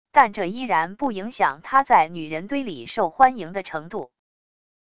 但这依然不影响他在女人堆里受欢迎的程度wav音频生成系统WAV Audio Player